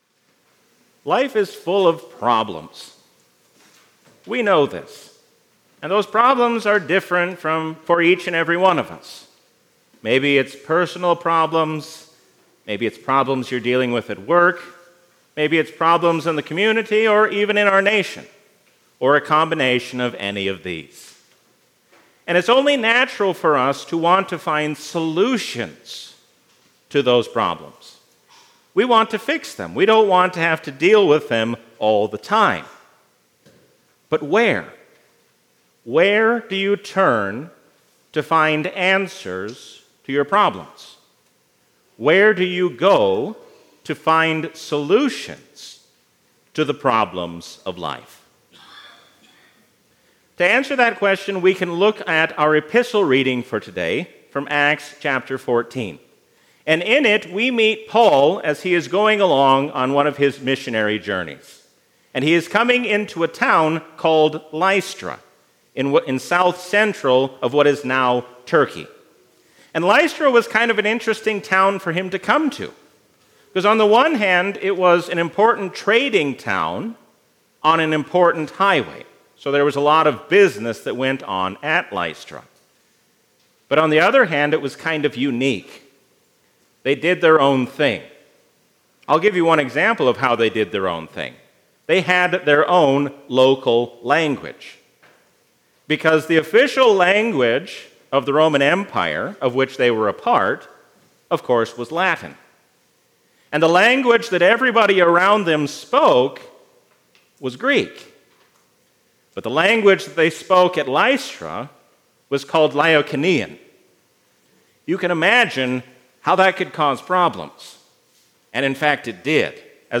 Sermon
A sermon from the season "Trinity 2022."